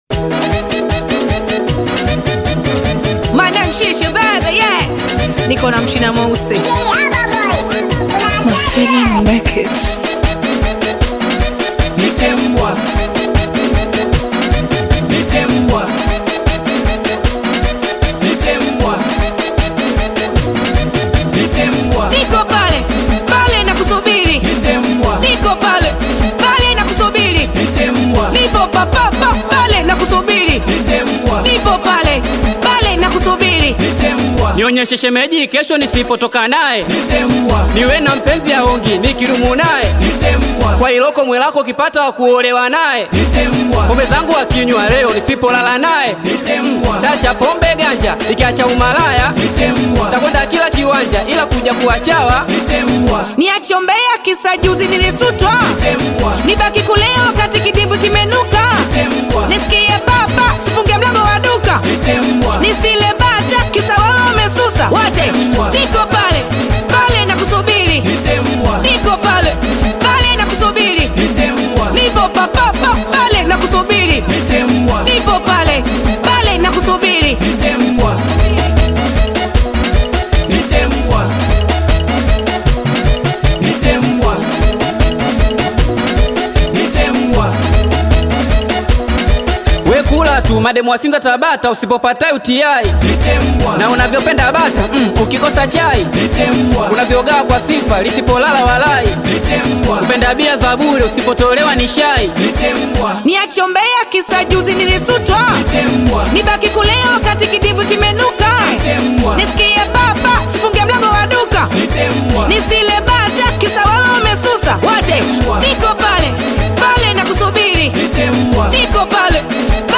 Tanzanian bongo flava artist, singer
Singeli song
African Music